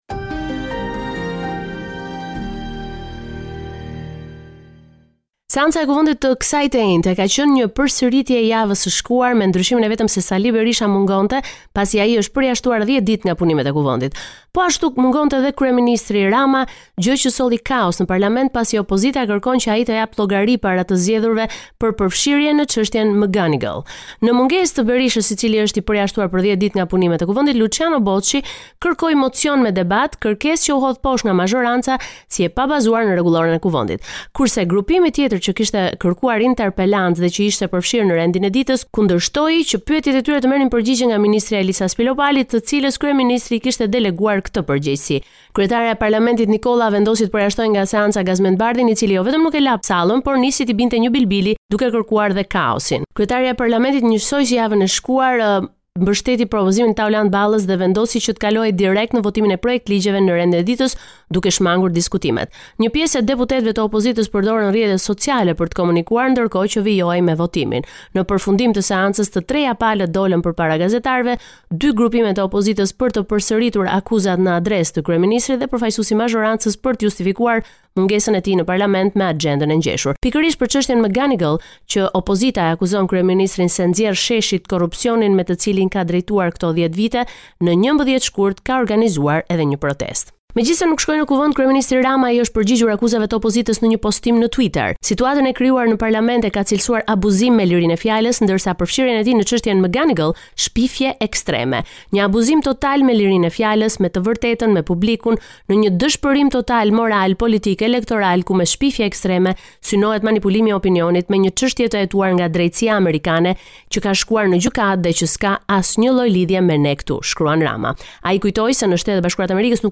Raporti me të rejat më të fundit nga Shqipëria.